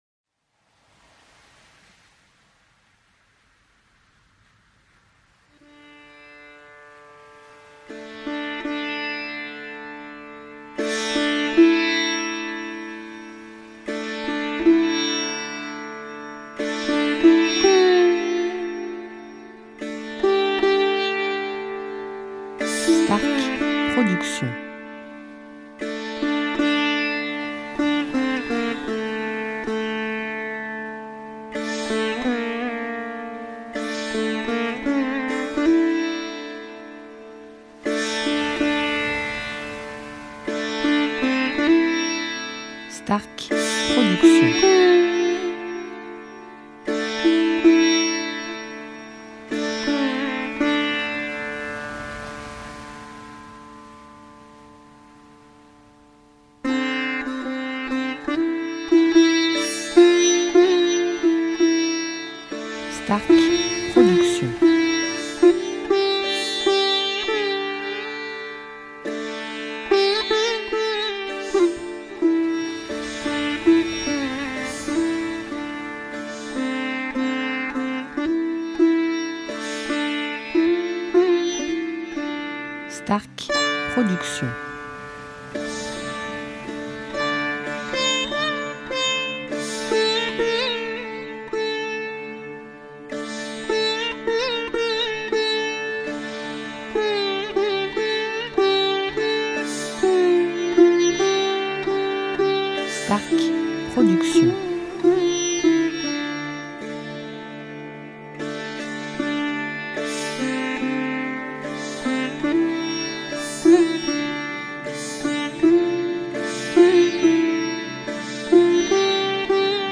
style Ayurvédique durée 1 heure